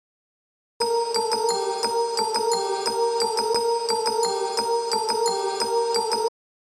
Play, download and share bells 1 original sound button!!!!